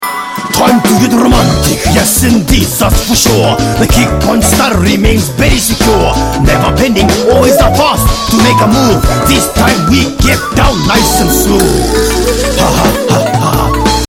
parappa2-romantic.mp3